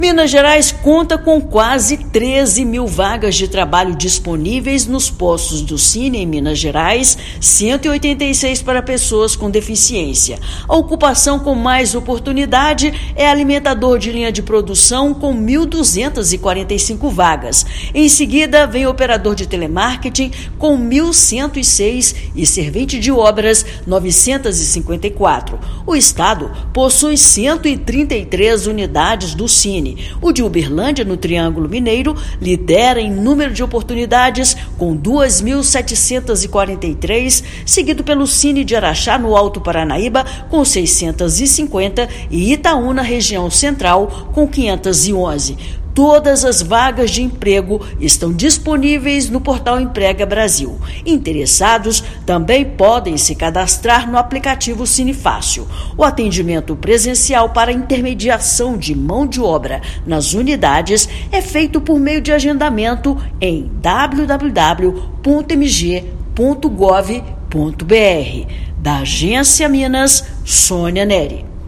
Alimentador de linha de produção e operador de telemarketing são as ocupações com mais oportunidades. Ouça matéria de rádio.